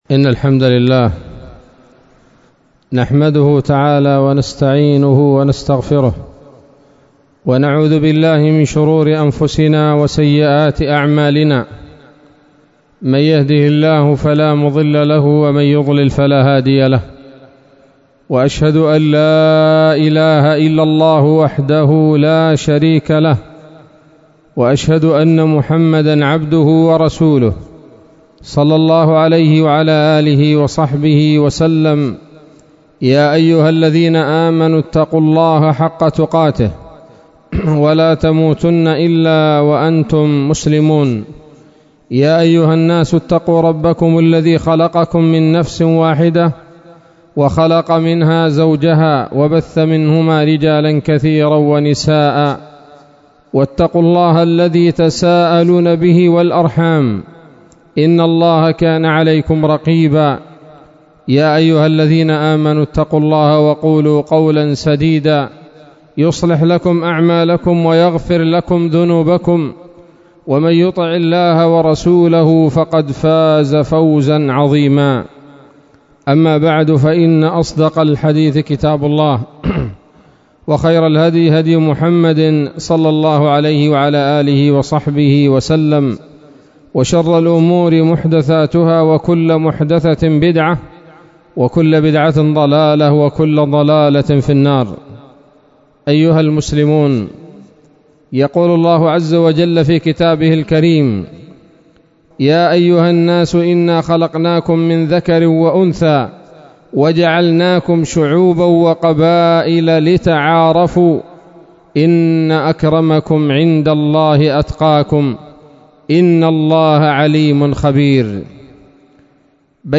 خطبة جمعة بعنوان: (( فضائل القبائل اليمنية )) 24 صفر 1443 هـ